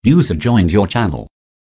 user.joined.wav